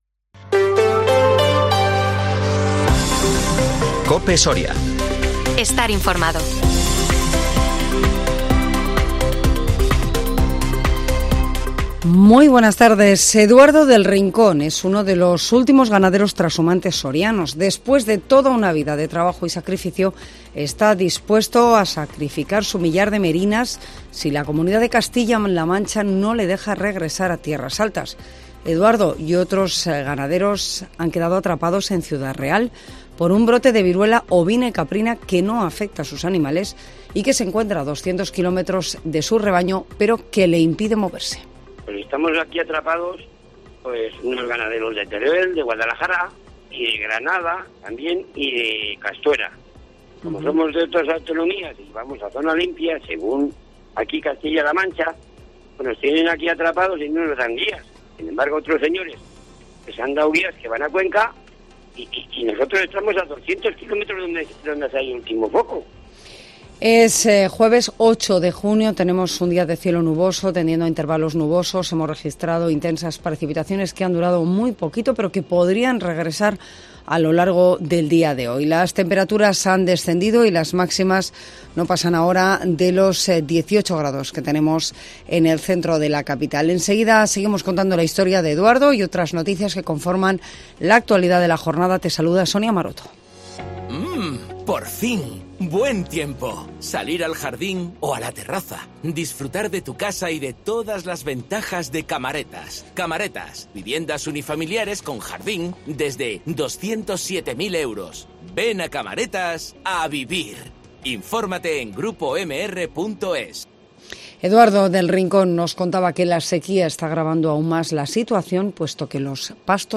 INFORMATIVO MEDIODÍA COPE SORIA 8 JUNIO 2023